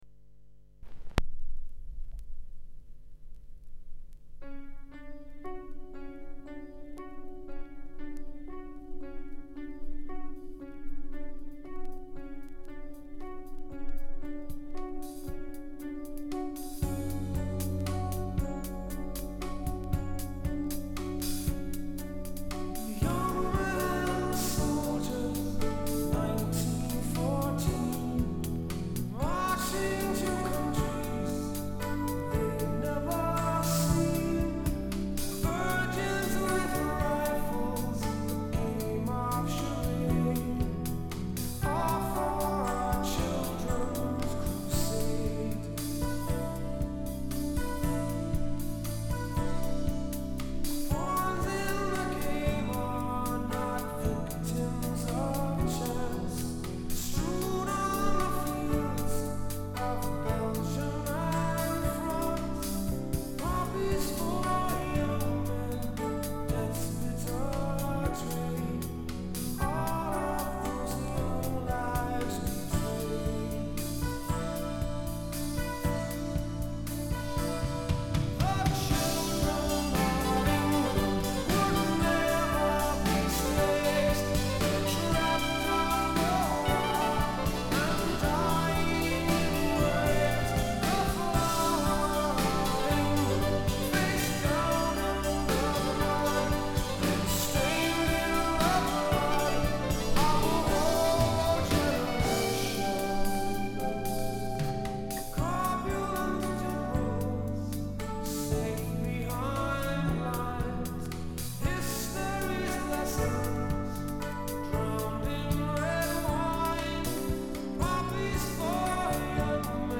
VG[++] – Very Good[++] – на пластинке возможны внешние царапины или потертости, не вызывающие щелчки, или вызывающие незначительные малоощутимые щелчки. При прослушивании могут быть слышны незначительные шумы и потрескивания. Зачастую это слышно только в тихих местах.